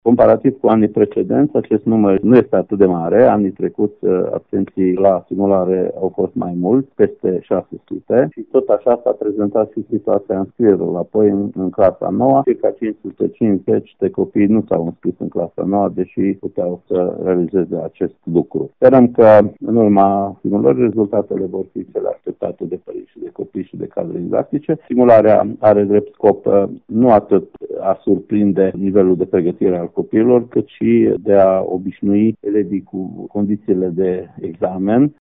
Inspectorul şcolar general al judeţului Mureş, Ştefan Someşan, a declarat că absenteismul de la această probă este mai mic comparativ cu alţi ani.